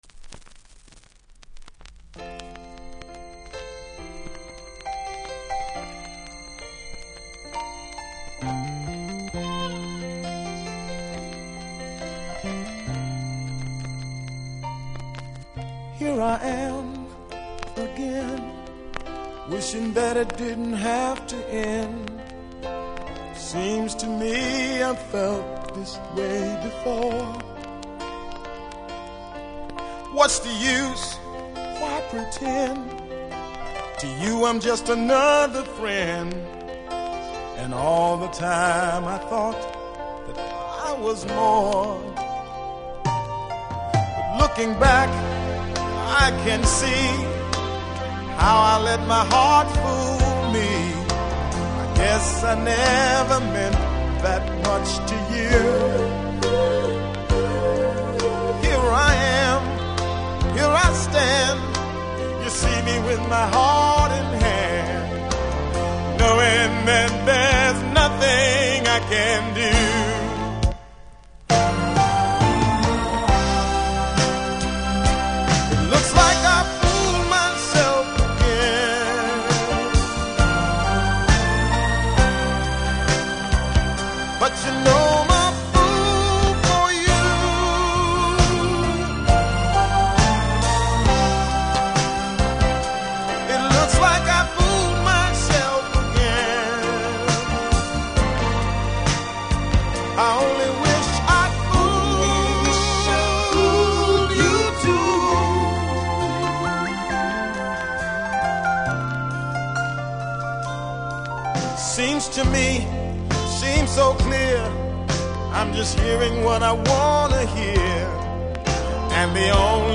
序盤ノイズ感じますので試聴で確認後購入下さい。
途中から入るベースがジャマイカっぽくてシステムで聴くと最高の1枚